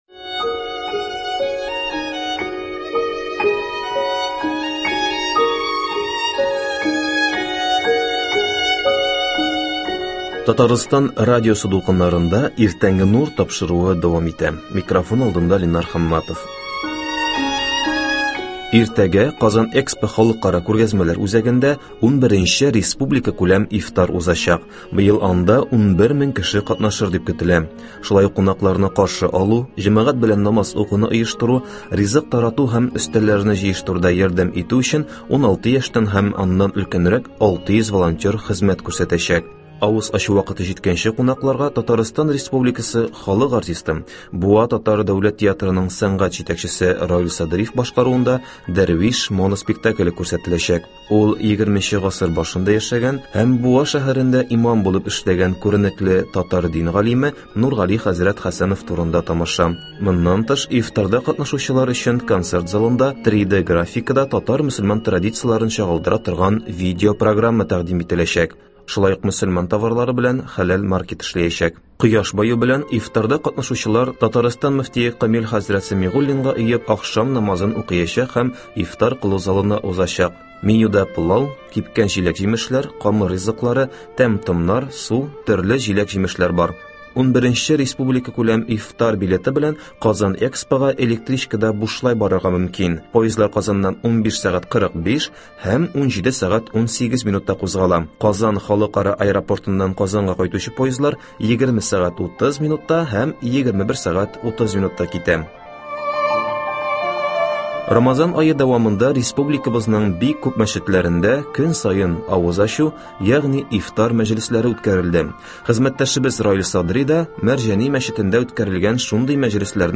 Мәрҗани мәчетендә ифтар (14.04.23)